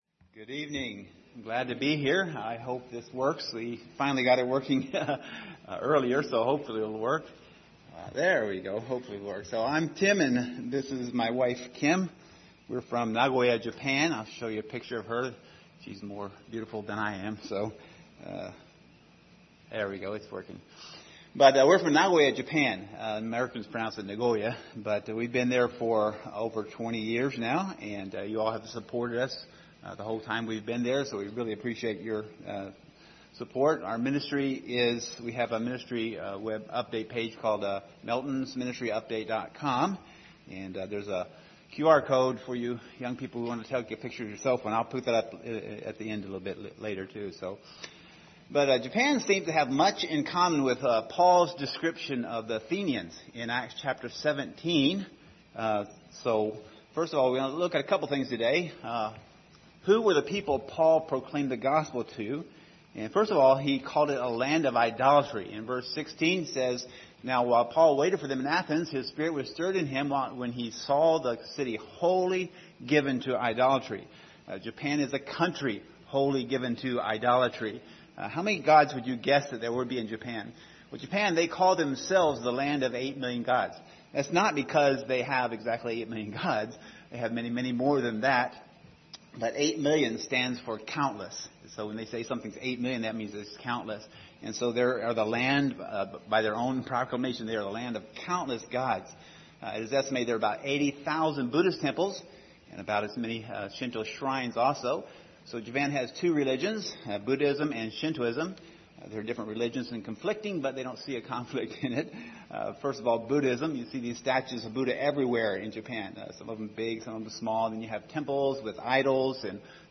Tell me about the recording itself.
Passage: Acts 17 Service Type: Sunday Evening